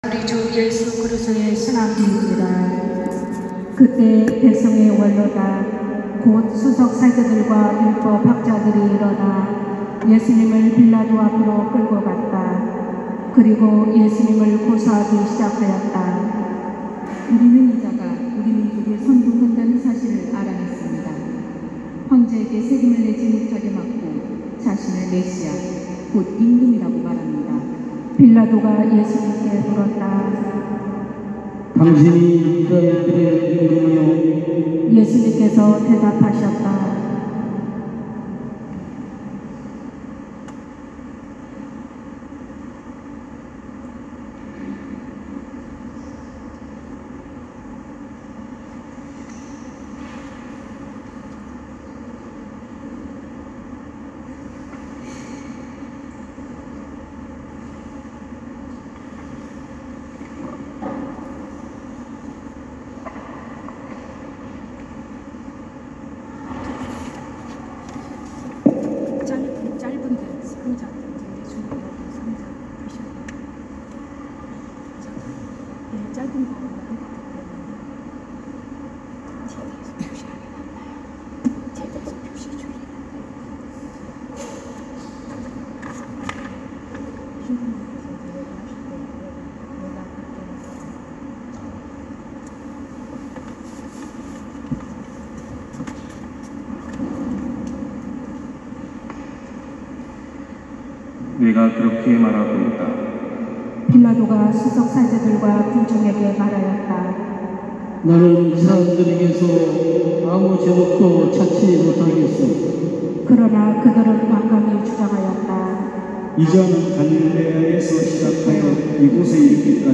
250412 신부님 강론말씀